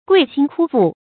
刿心刳腹 guì xīn kū fù
刿心刳腹发音